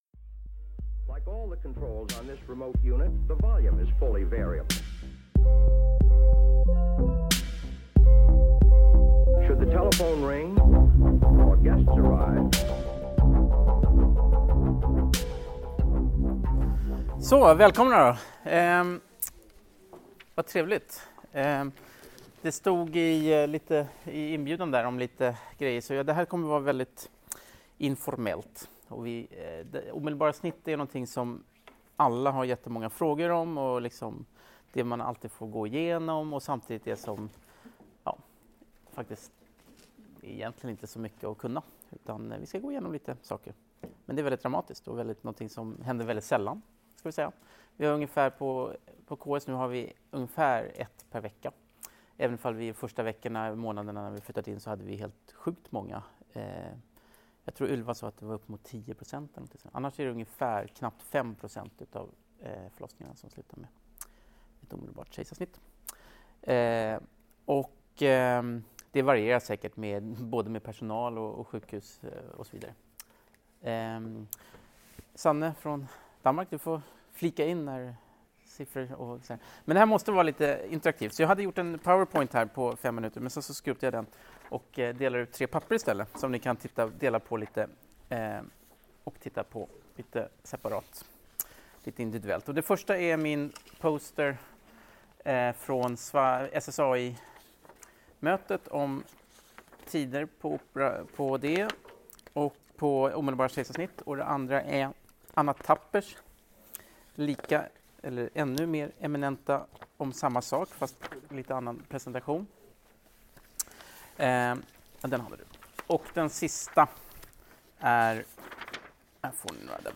Föreläsningen hölls på Karolinska i Solna 2019-02-18.